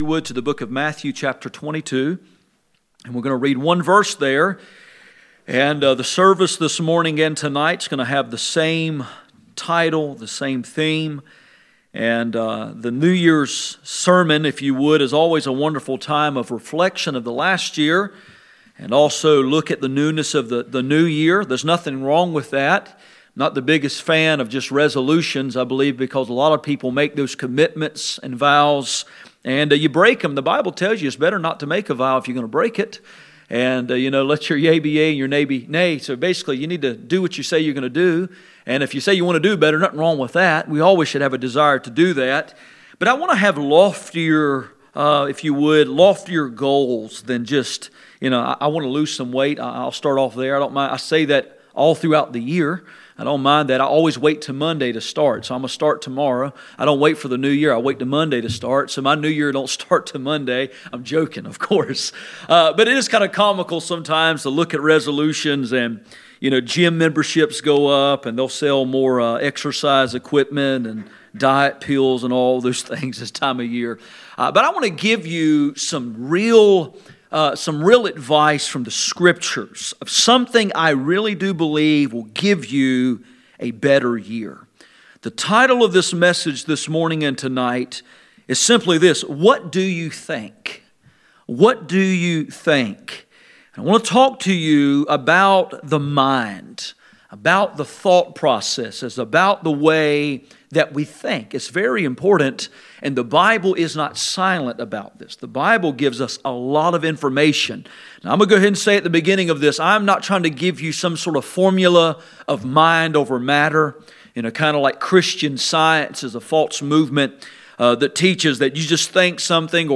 None Passage: Matthew 22:37 Service Type: Sunday Morning %todo_render% « Final Words from a Faithful Preacher What do you think?